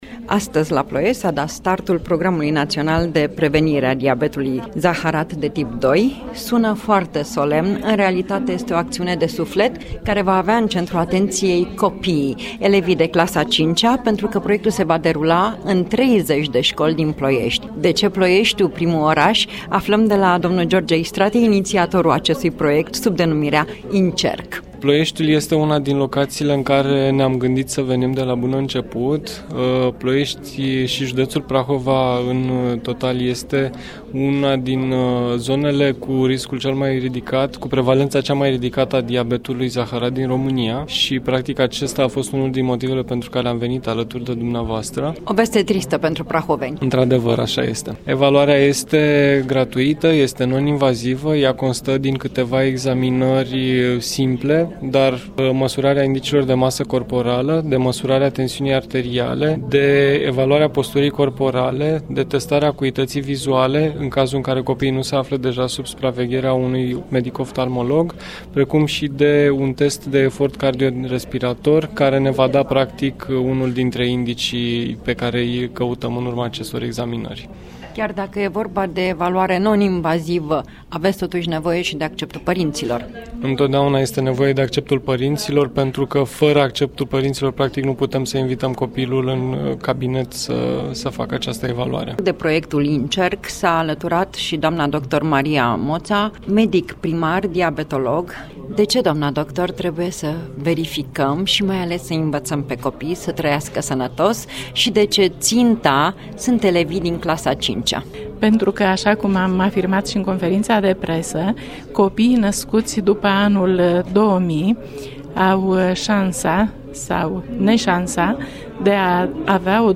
[AUDIO] Interviu Radio România Actualități, despre lansarea Programului “înCerc”
Reportaj radio difuzat la Radio Romania Actualități, în data de 26 septembrie 2017, despre lansarea Programului “înCerc” la Ploiești.